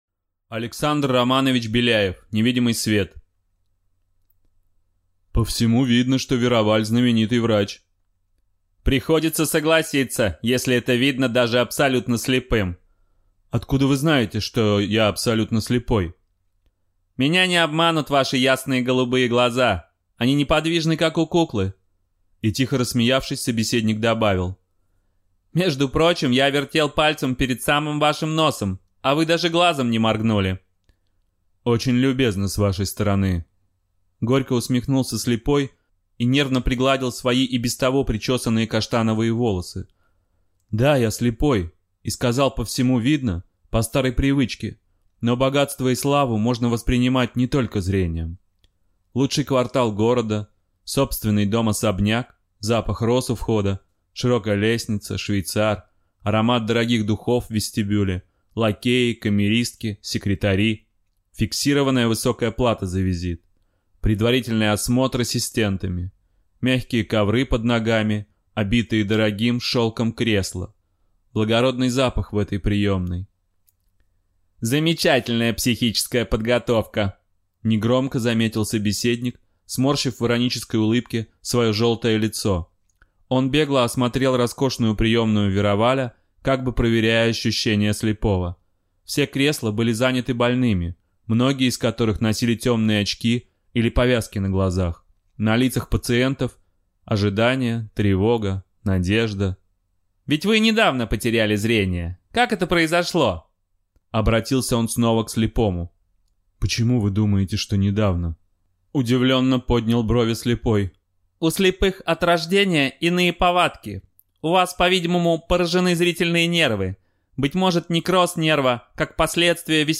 Аудиокнига Невидимый свет | Библиотека аудиокниг